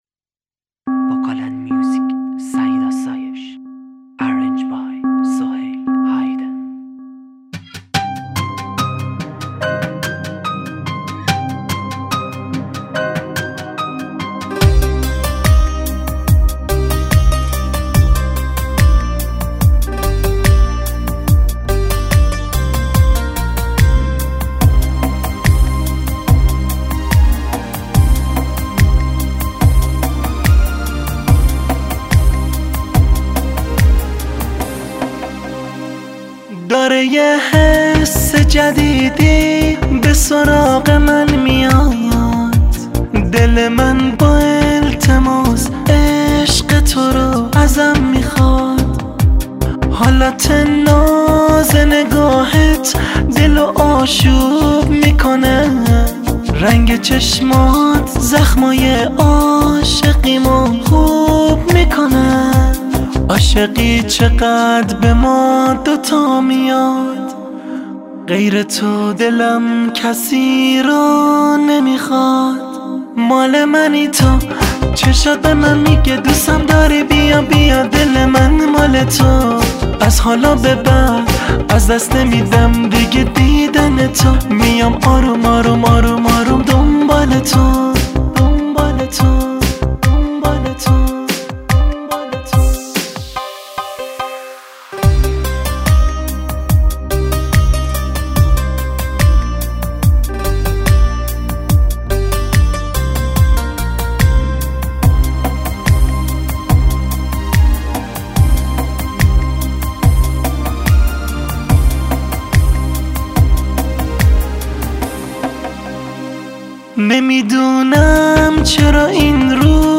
آهنگ احساسی آهنگ قدیمی